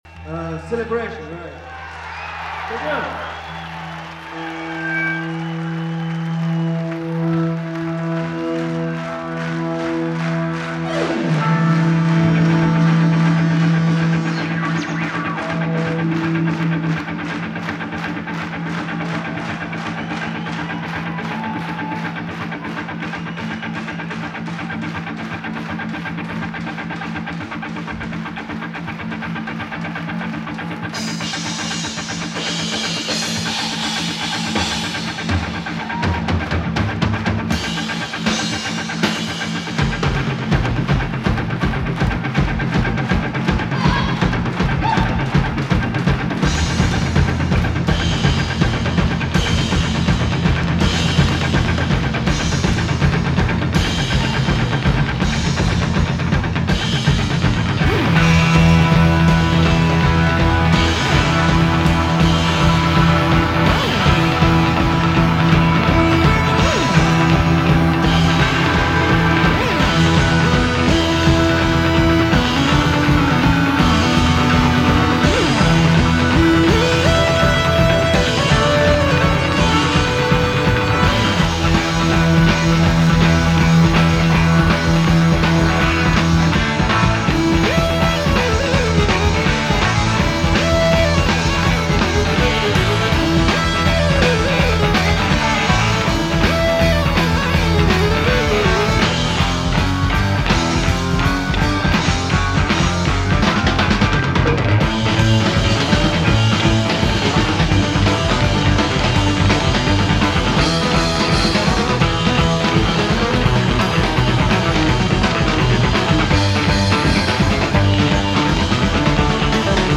live album